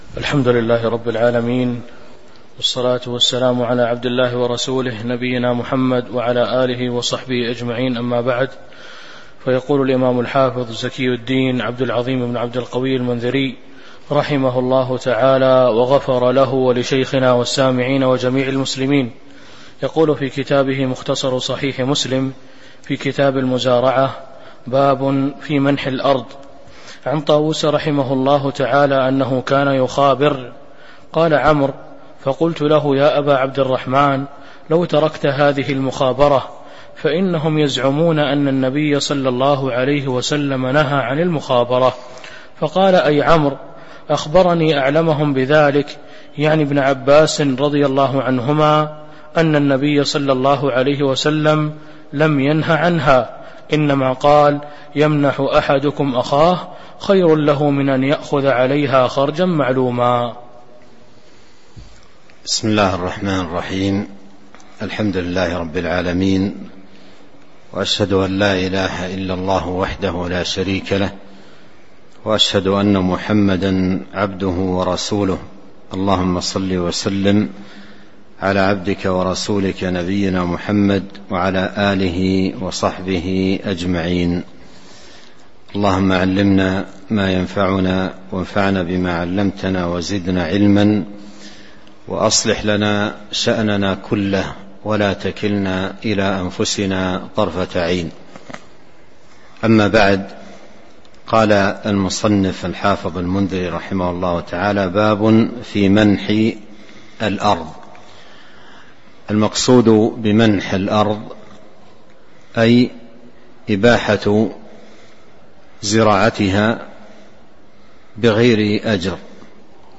تاريخ النشر ١٤ صفر ١٤٤٣ هـ المكان: المسجد النبوي الشيخ: فضيلة الشيخ عبد الرزاق بن عبد المحسن البدر فضيلة الشيخ عبد الرزاق بن عبد المحسن البدر باب في منح الأرض (02)